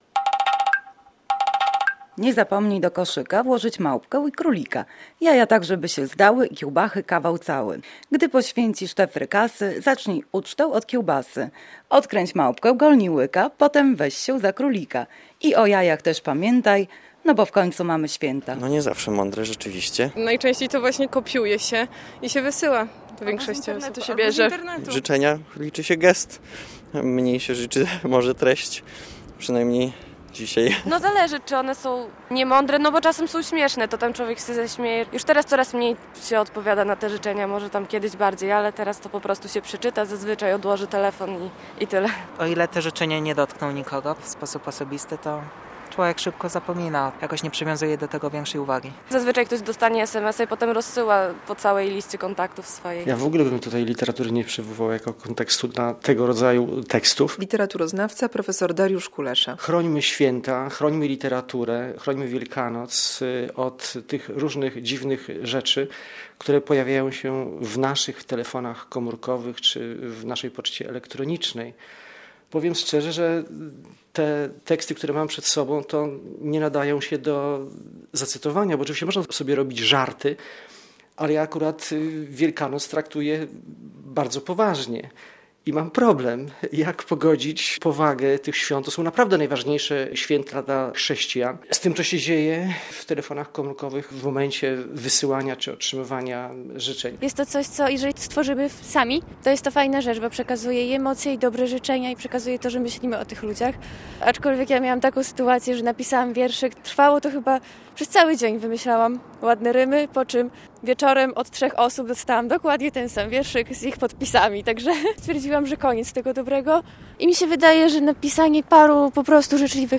Wielkanocne życzenia przez SMS - relacja